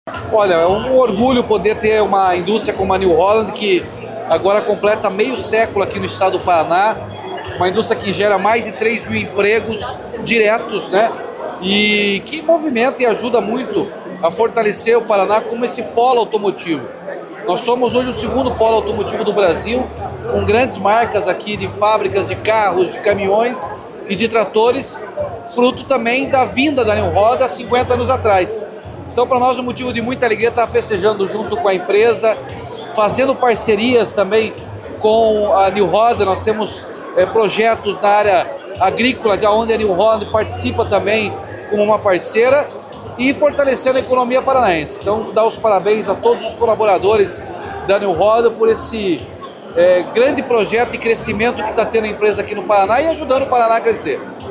Sonora do governador Ratinho Junior sobre os 50 anos da New Holland no Paraná